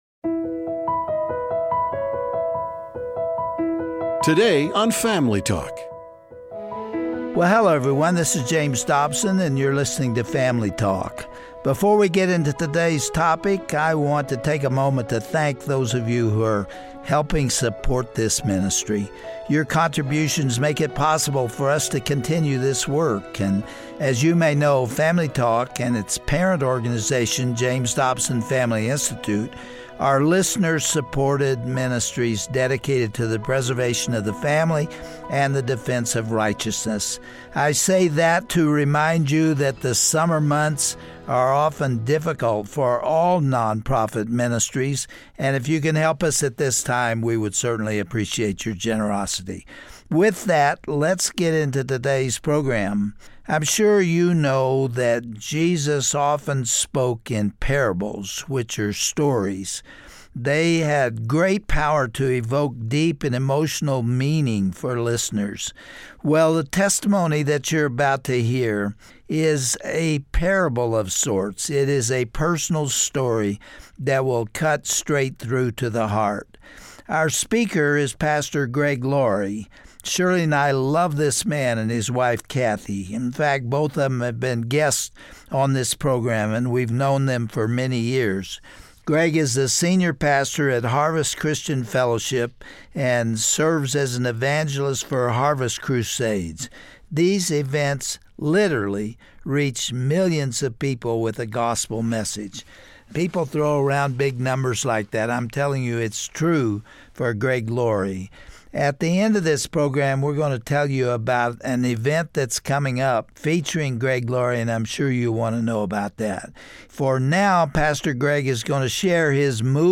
Pastor Greg Laurie from Harvest Christian Fellowship shares his powerful testimony involving a troubled upbringing. He explains how his mothers wild lifestyle led him down a similarly destructive path, and how his longing for purpose led to a meaningful relationship with Jesus.